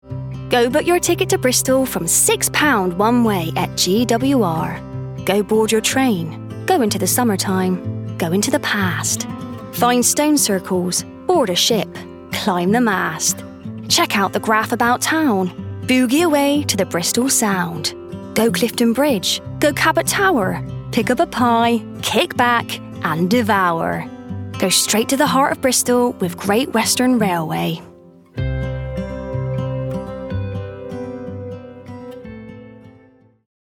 West-Country Accent Showreel
Female
Bright